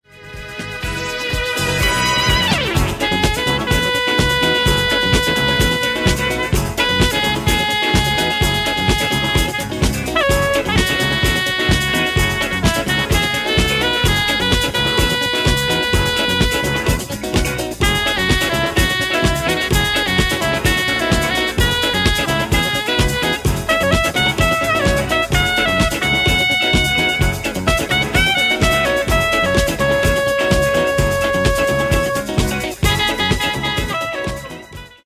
Genere:   Jazz Funk